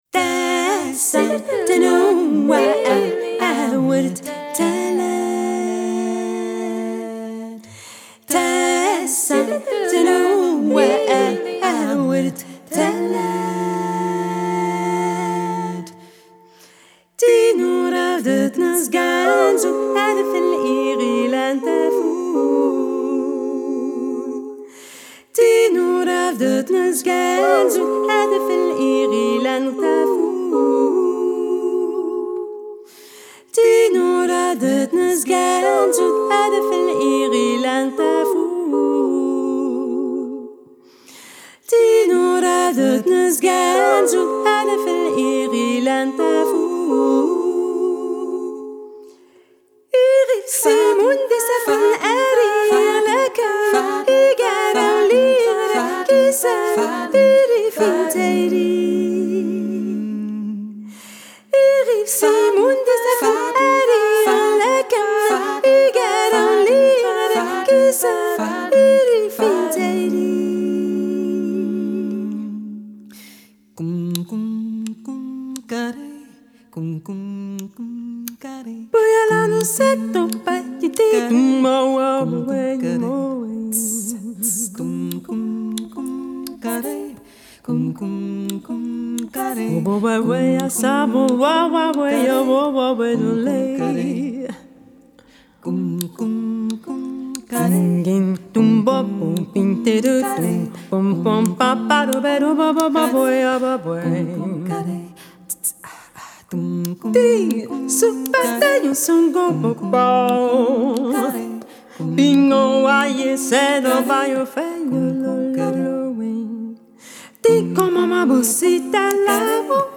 an evocative a cappella vocal quartet
they fashion lush harmonies and strong rhythmic drive
Genre: Jazz, Vocal, A Capella